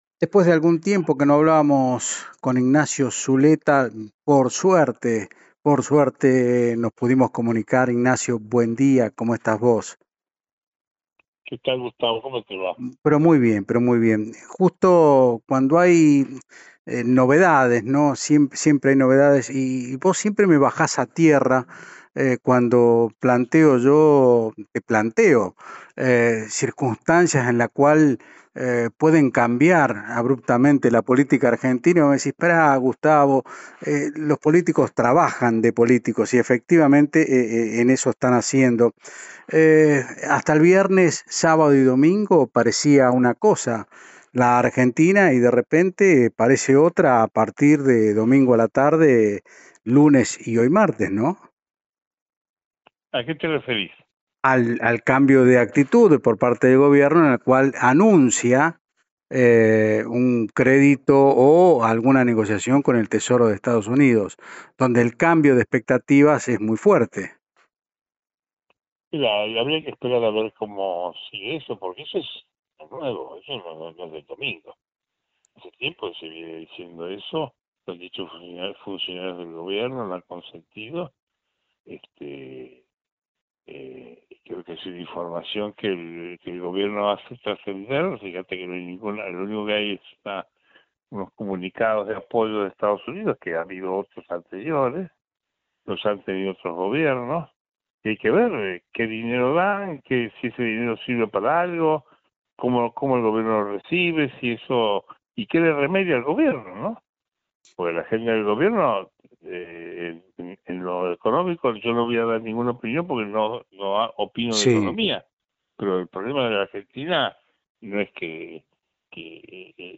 LA BRASA EN LA MANO O CÓMO EL GOBIERNO SE ILUSIONA CON SALVARSE EN POLÍTICA CON UN GOLPE ECONÓMICO (Un diálogo por radio)